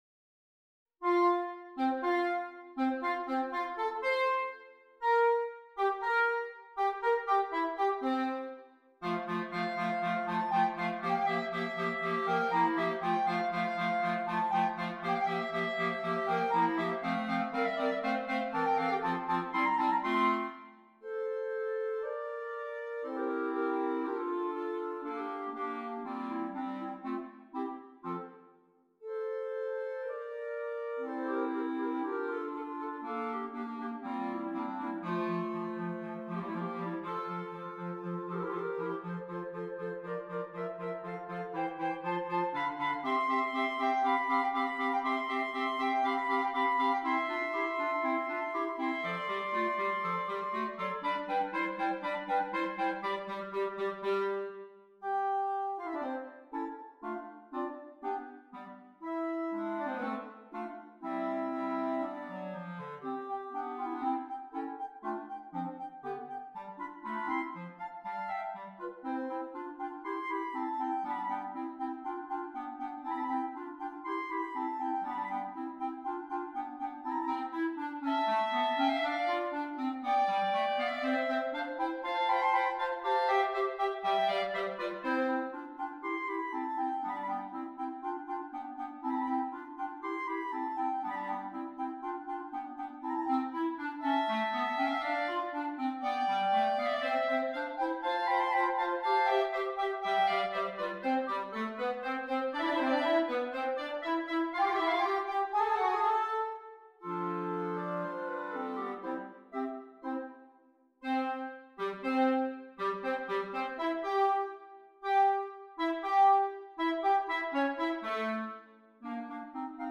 4 Clarinets
All parts are interesting and exciting.